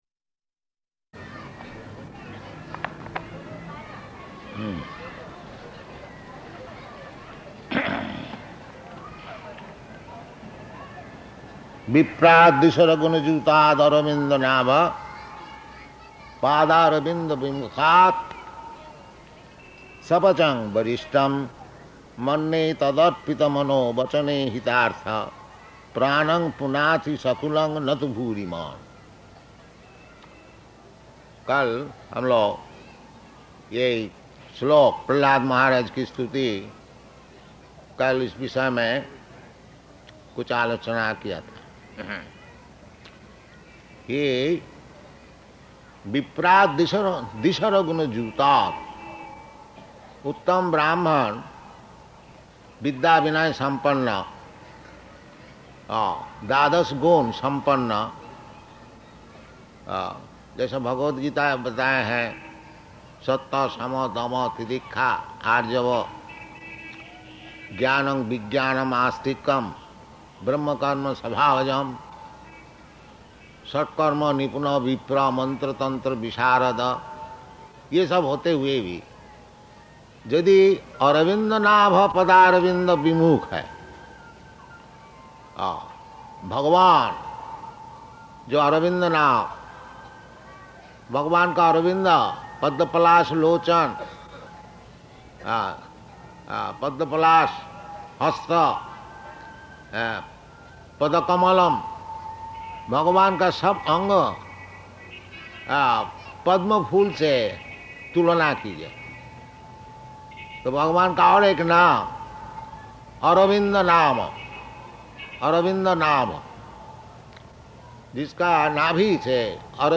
Lecture in Hindi
Type: Lectures and Addresses
Location: Ahmedabad